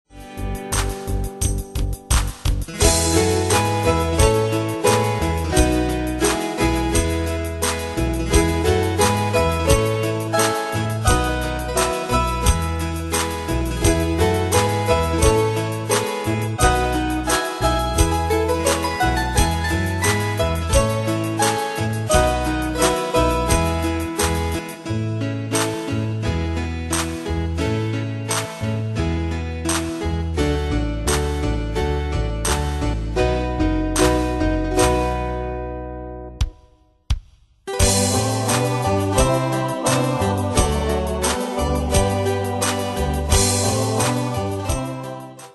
Style: PopAnglo Ane/Year: 1991 Tempo: 87 Durée/Time: 3.26
Danse/Dance: Ballade Cat Id.
Pro Backing Tracks